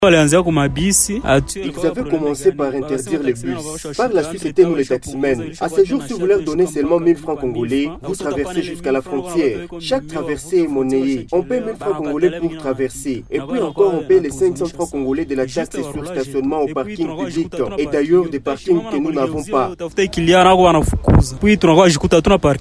Dans une interview avec un reporter de Radio Maendeleo ce jeudi 28 novembre 2024, ces conducteurs indiquent que ces éléments ont abandonné le travail de régulation pour se constituer en agents rançonneurs en imposent 1000FC à chaque chauffeur qui veut aller vers la frontière RUZIZI 1er.